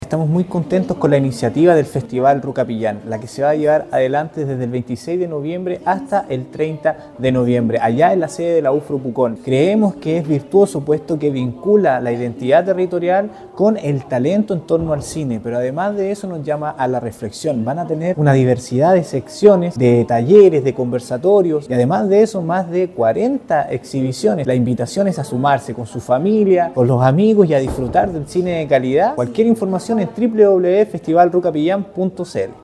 Un colorido y formal lanzamiento tuvo hace algunos días la séptima versión del Festival de Cine Rukapillan Pucón 2025, el que vivió esta jornada estelar y “en sociedad” en la Universidad de La Frontera (UFRO) en Temuco, en donde fueron convocados autoridades y prensa local, adelantando el nutrido programa de este certamen fijado entre el 26 y 30 de noviembre próximo en el campus Pucón de la entidad educativa regional.
Eric-Iturriaga-Seremi-de-Culturas-Artes-y-Patrimonio-apoya-el-evento-.mp3